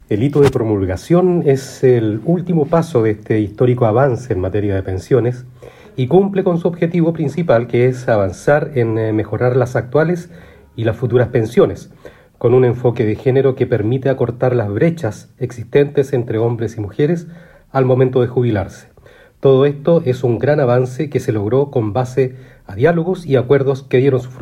En la región de Coquimbo el Seremi del Trabajo y Previsión Social, Francisco Brizuela, destacó la Reforma de Pensiones y manifestó que
Seremi-del-Trabajo-24031.mp3